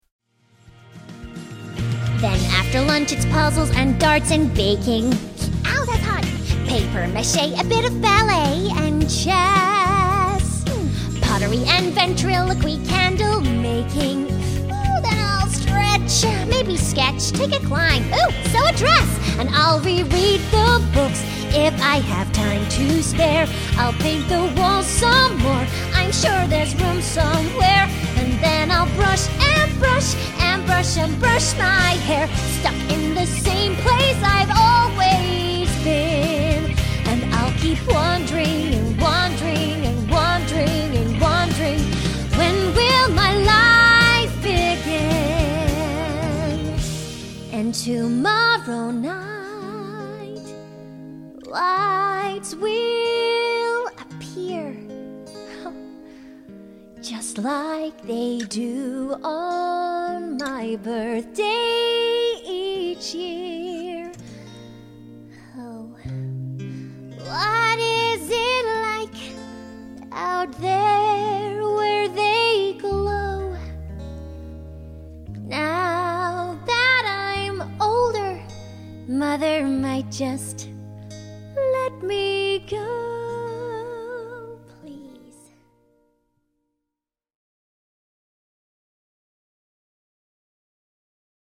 Bright 'n' breezy, versatile, with perfect comic timing.
Kids Voices young girls (multiple accents)